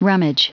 Prononciation du mot rummage en anglais (fichier audio)
Prononciation du mot : rummage